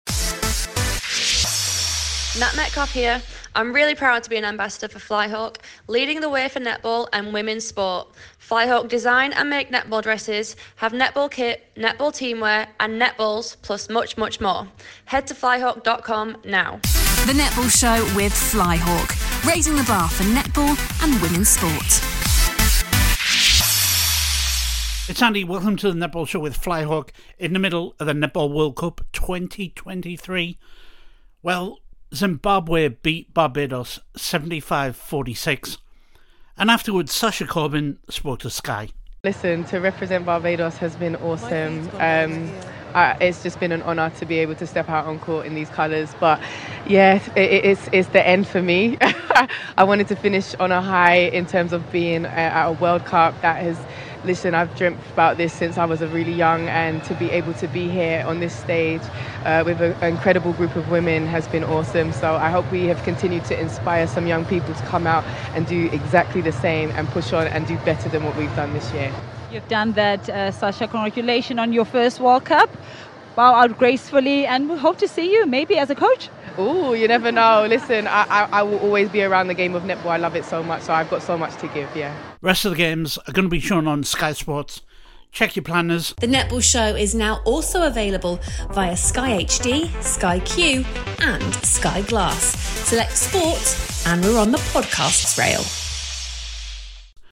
Sasha Corbin has been speaking to Sky following Barbados' loss to Zimbabwe